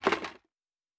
Plastic Foley Impact 1.wav